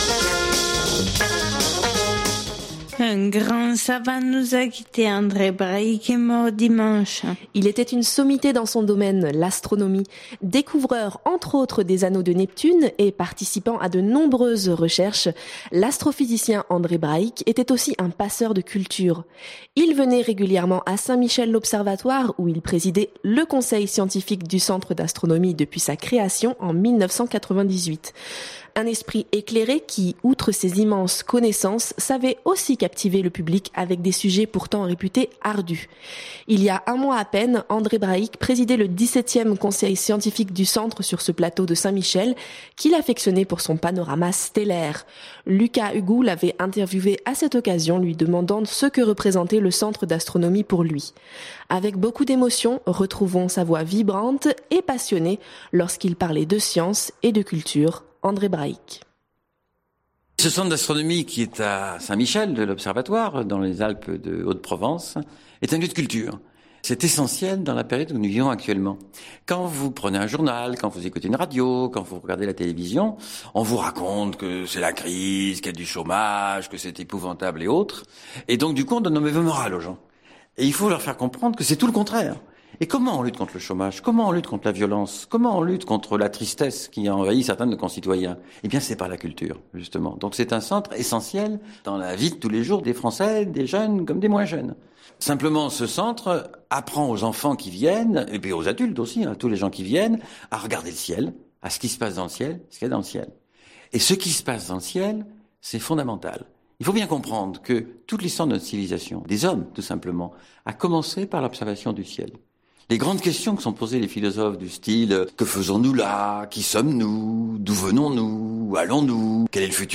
Avec beaucoup d’émotion, retrouvons sa voix vibrante et passionnée lorsqu’il parlait de science et de culture.